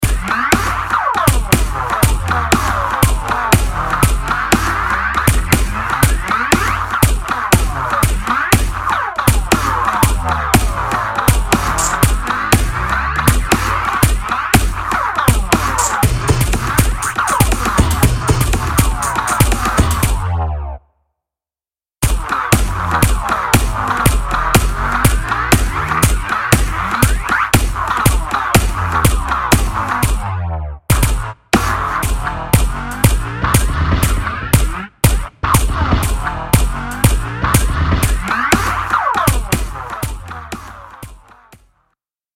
distinctive and attention commanding drum loop
abstract room filling sounds with booming kick
and a wandering synthetic sound that can make a